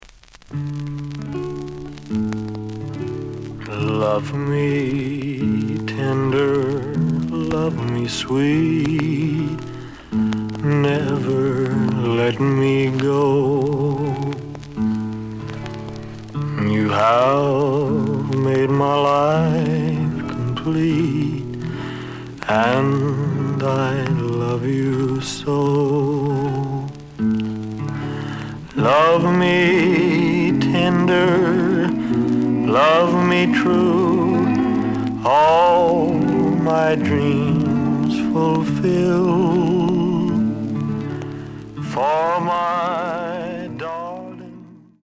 The audio sample is recorded from the actual item.
●Genre: Rhythm And Blues / Rock 'n' Roll
Some click noise on both sides due to scratches.